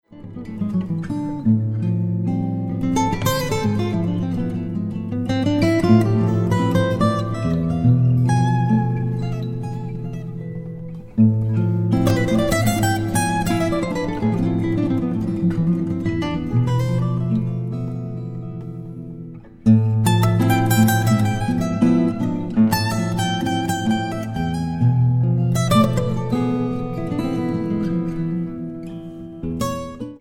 acoustic guitars
guitar: Taylor 712C (1980)